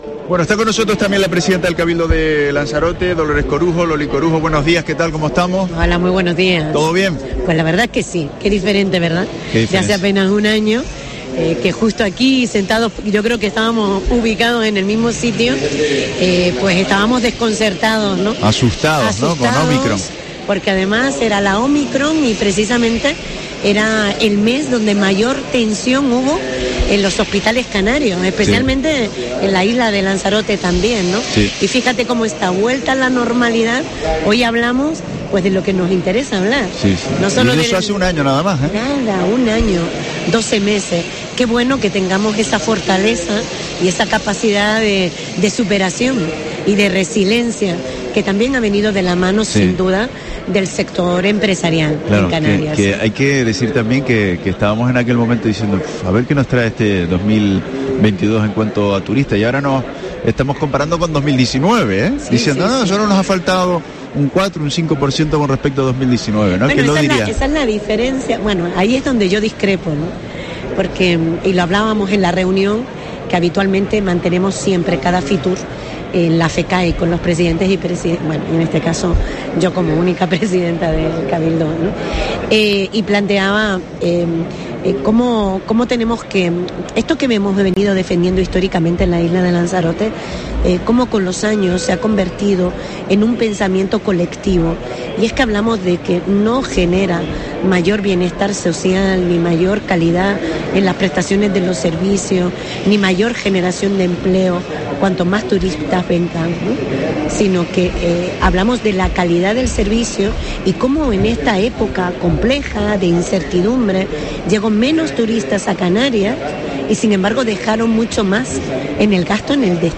Entrevista a María Dolores Corujo, presidenta del cabildo de Lanzarote